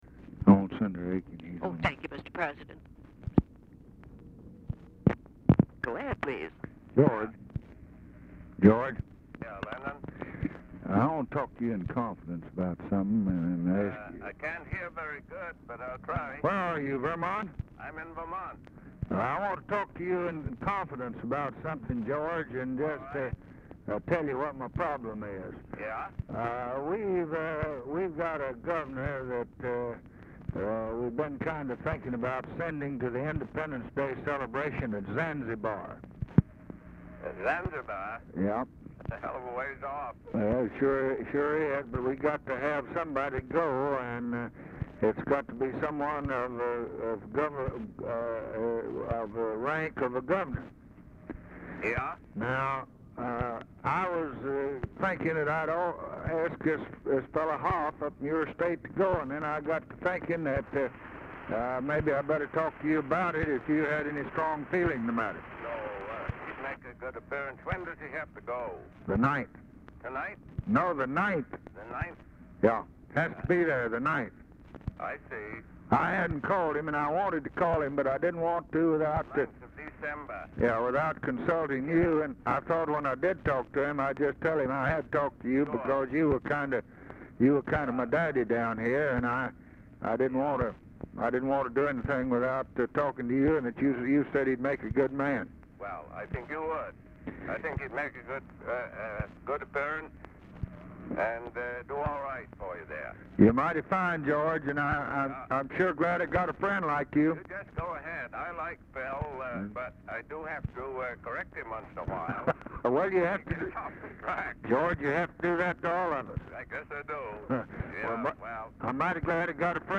Telephone conversation # 192, sound recording, LBJ and GEORGE AIKEN, 11/30/1963, 2:15PM | Discover LBJ
Format Dictation belt
Location Of Speaker 1 Oval Office or unknown location